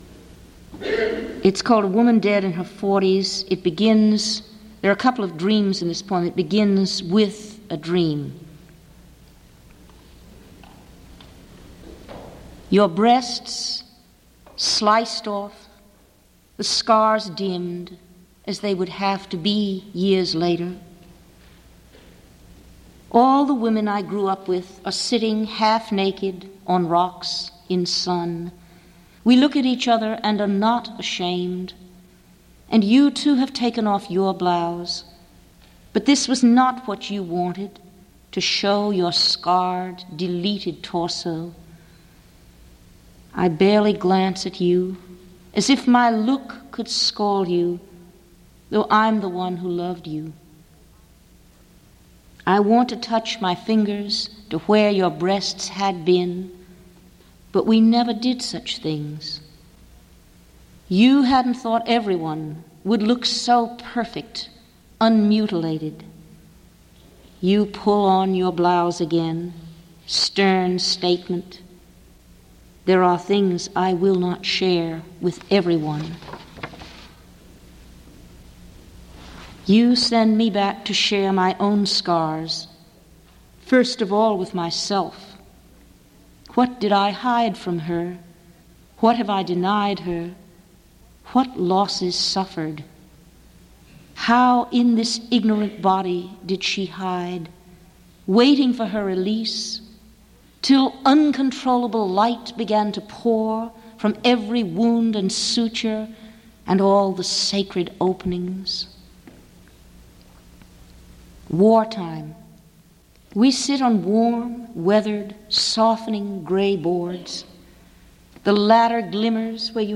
Audio files here are from Rich’s recordings for the University of Cincinnati’s Elliston Project; from the 92nd Street Y in New York City; from PennSound; and from the Voice of the Poet series edited by J.D. McClatchy in 2002 for Random House Audio.
(Cincinnati  11-05-79)